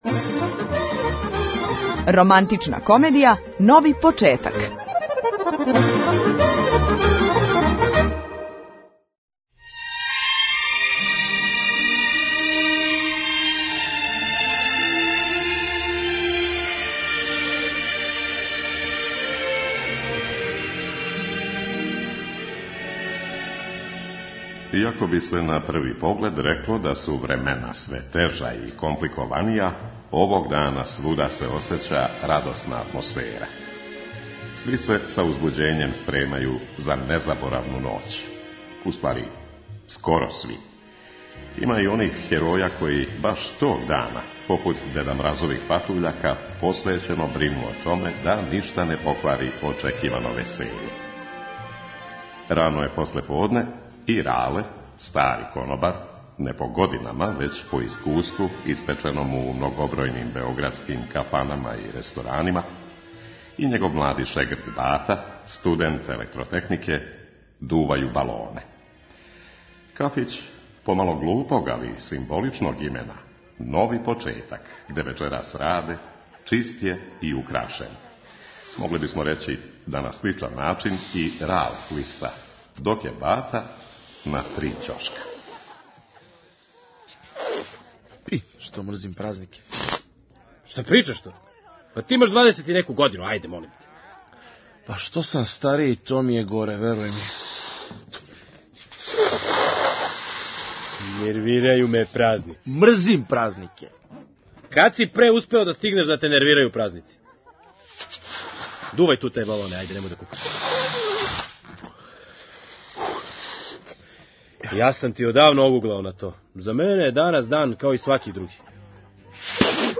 Романтична комедија "Нови почетак"